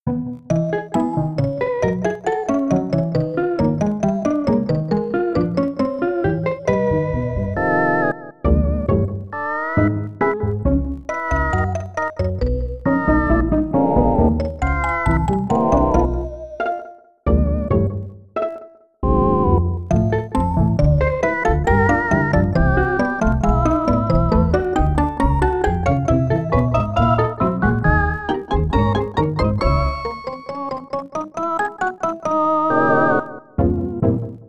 EXAMPLE 1: SNESMOD (properly tracked):
This one is about as good as you can get SNESMOD to sound (although the samples were not preemphasized). Though it's a little dry, it's mostly free from unwanted pops and crackles. You can hear a bit of that between 0:12 and 0:19, though, as well as at 0:26.